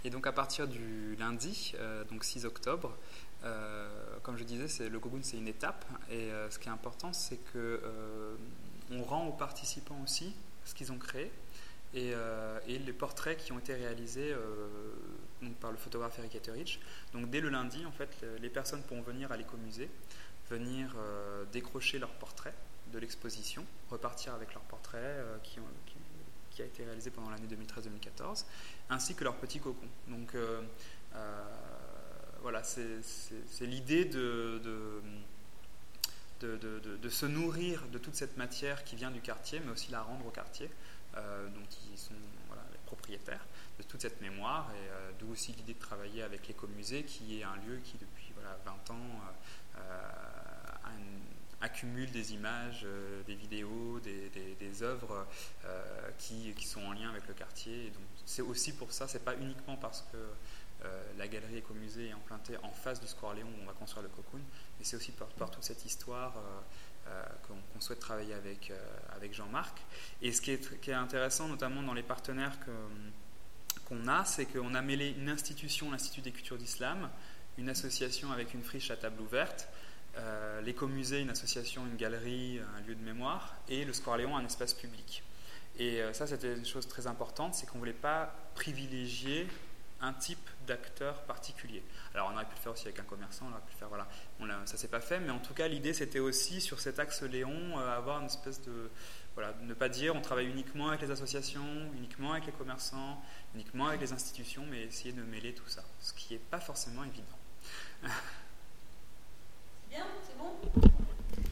Cocoon : interview